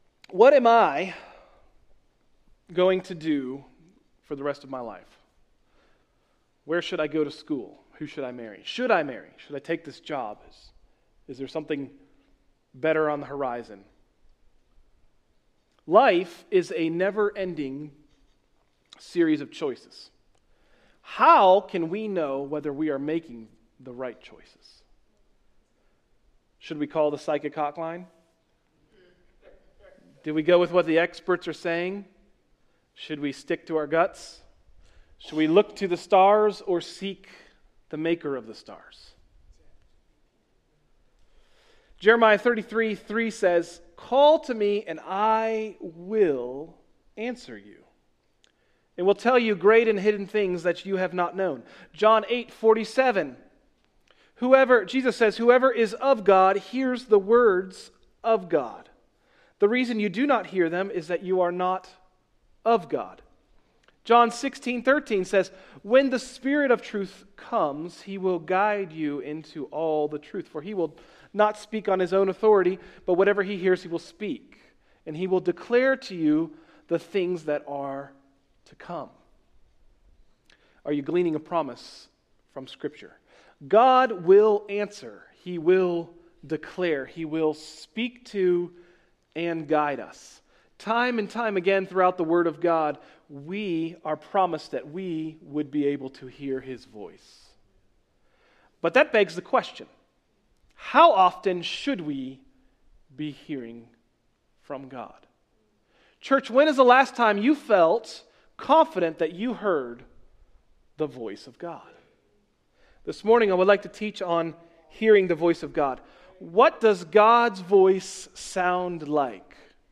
Message: “Discerning God’s Voice” – Tried Stone Christian Center